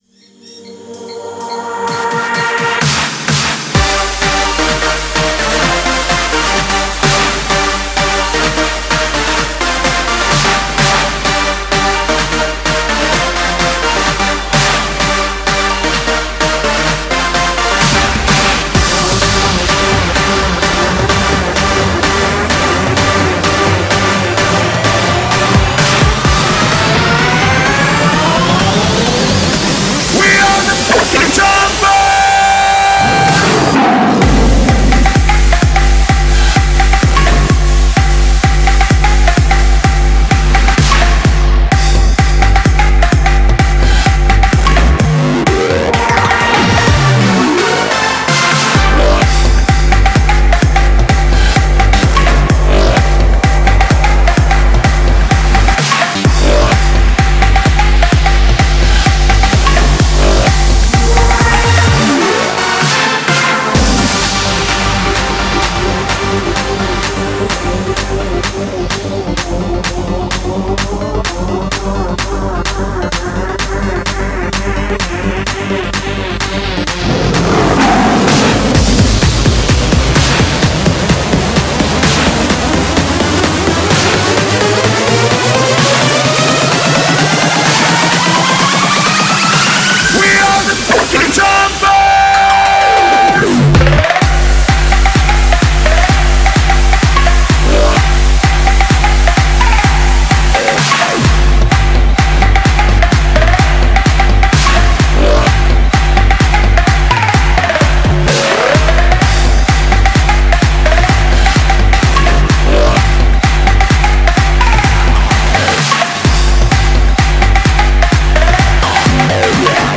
BPM128
Audio QualityPerfect (High Quality)
Comments[EDM]